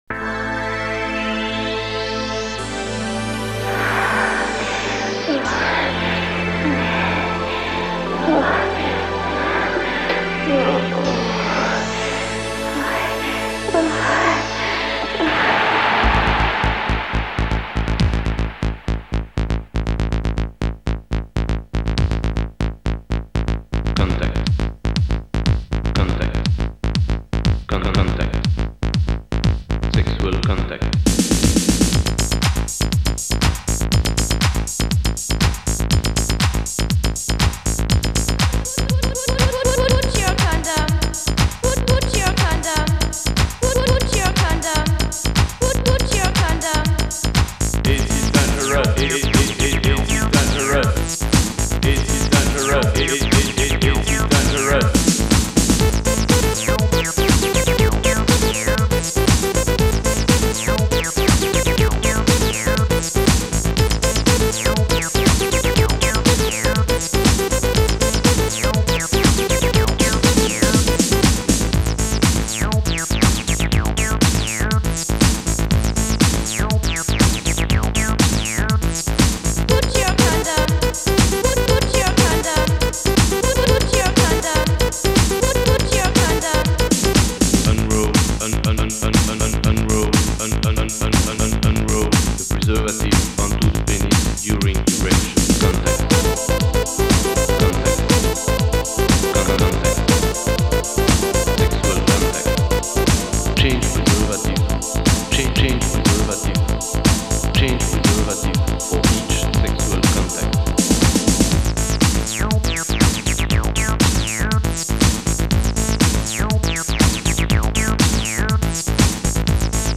Género: Acid.